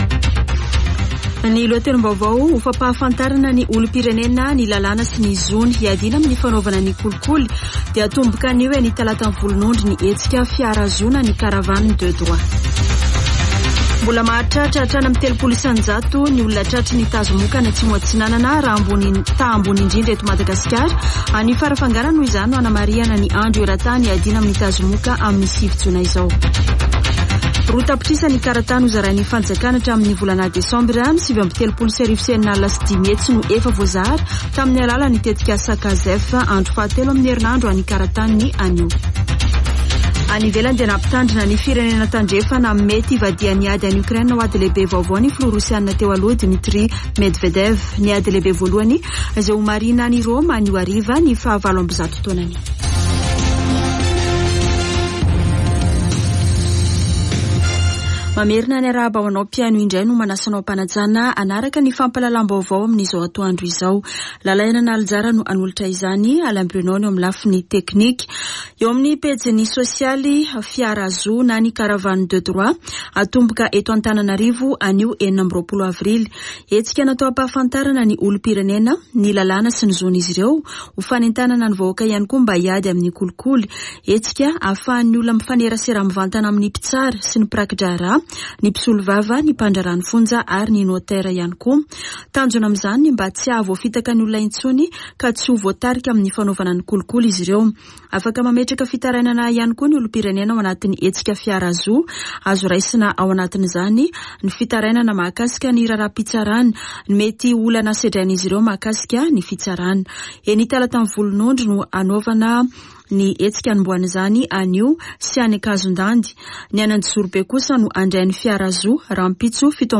[Vaovao antoandro] Alarobia 26 avrily 2023